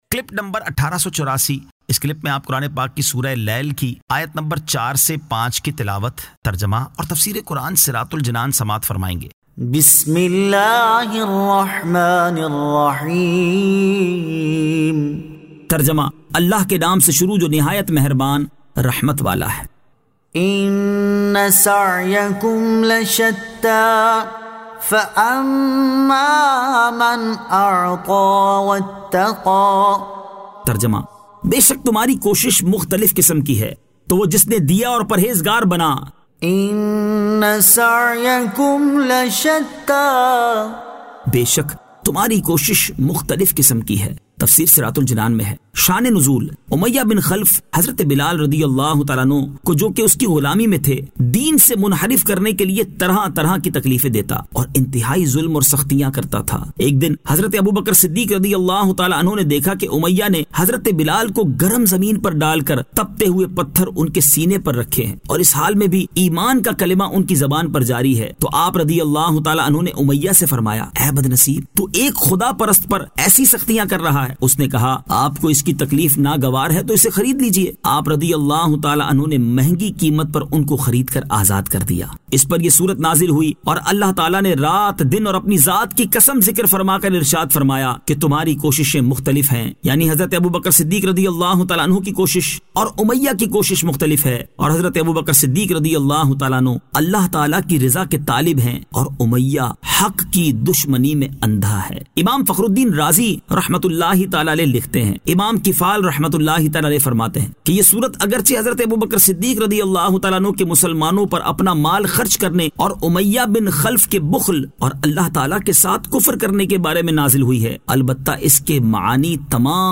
Surah Al-Lail 04 To 05 Tilawat , Tarjama , Tafseer
2025 MP3 MP4 MP4 Share سُوَّرۃُ الْلَیْلْ آیت 04 تا 05 تلاوت ، ترجمہ ، تفسیر ۔